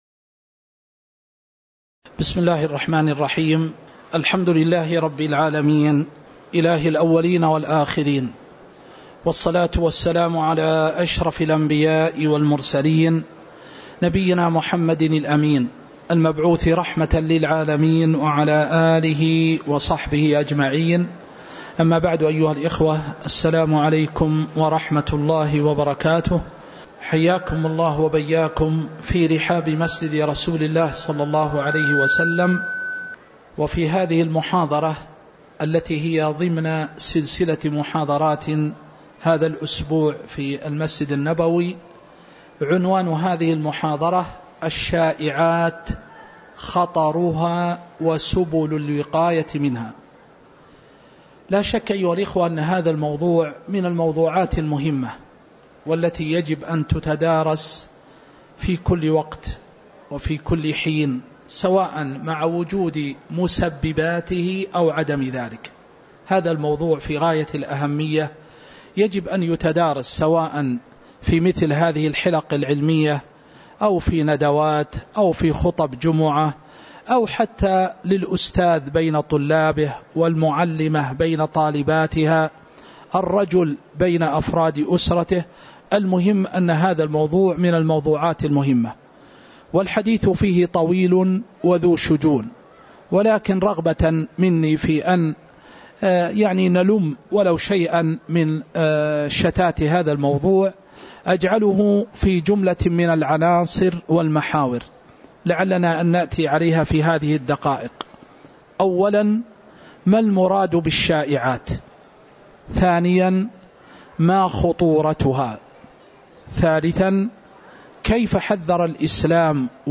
تاريخ النشر ٦ جمادى الأولى ١٤٤٥ هـ المكان: المسجد النبوي الشيخ